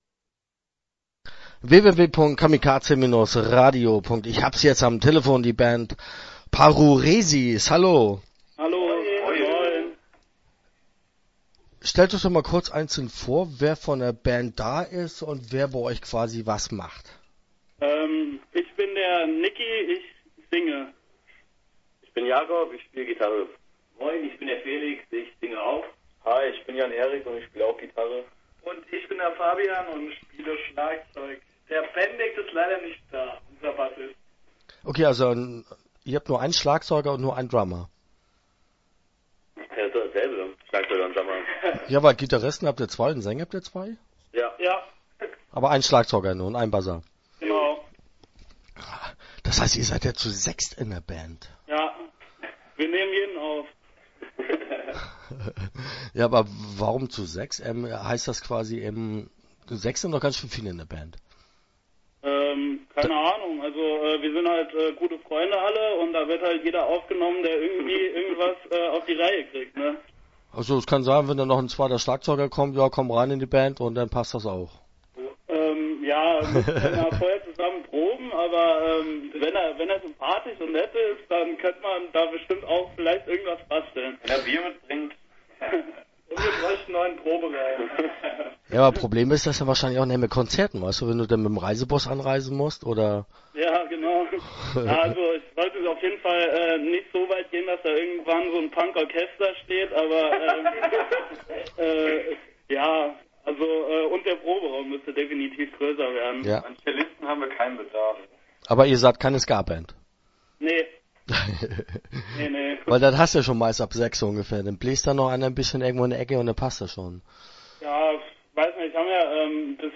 Paruresis - Interview Teil 1 (10:40)